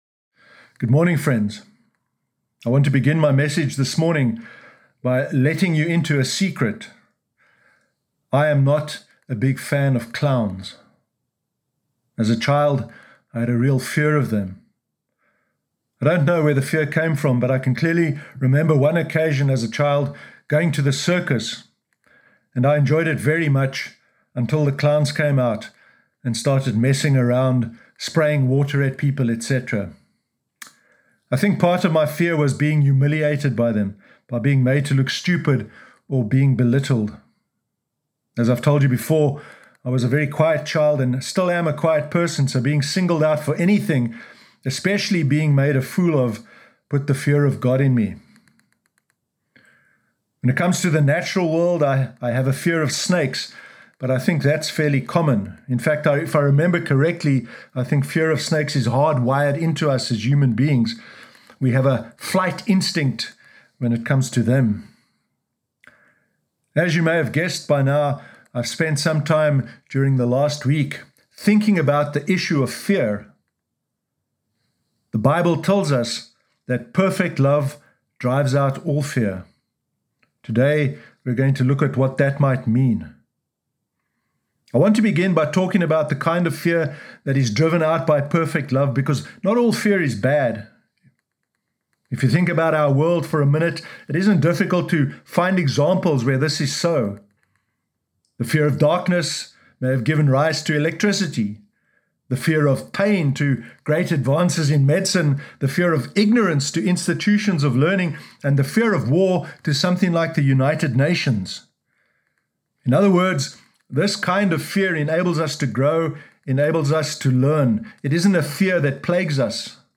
Sermon Sunday 9 August 2020